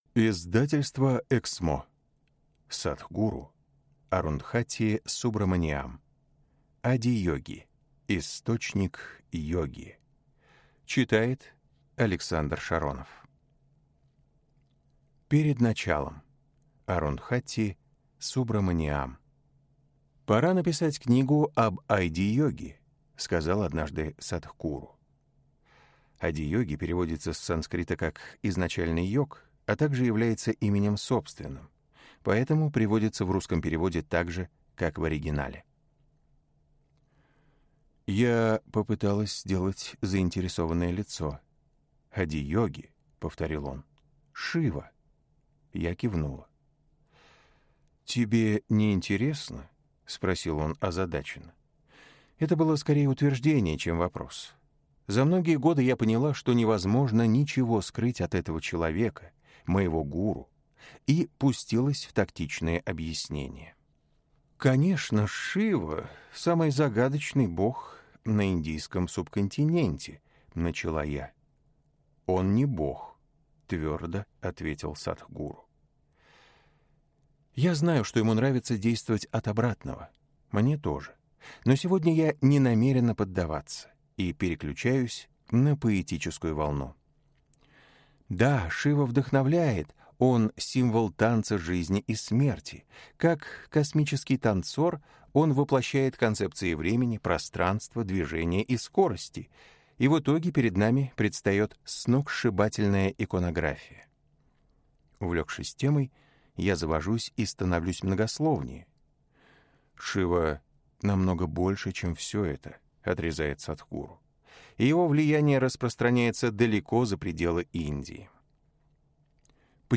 Аудиокнига Адийоги. Источник Йоги | Библиотека аудиокниг